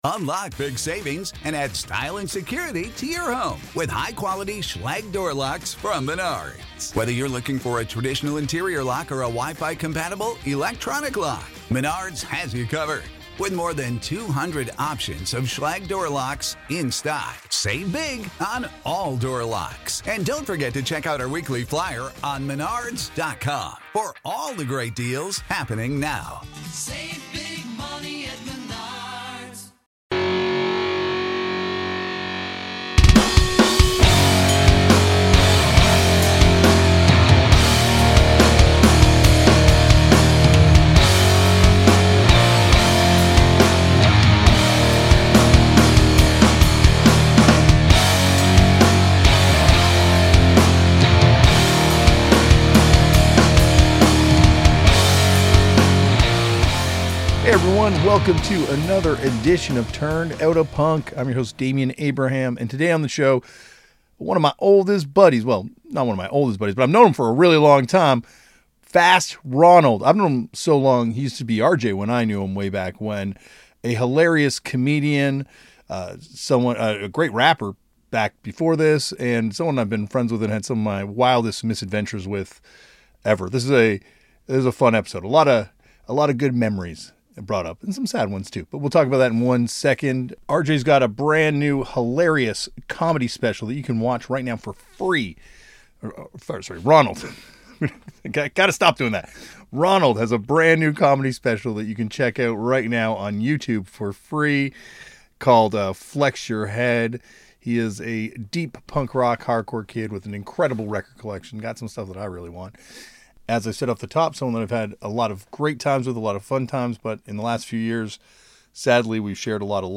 Each week, he sits down and chats with an interesting person from various walks of life to find out how their world was influenced and changed by the discovery of a novelty genre that supposedly died out in 1978... PUNK!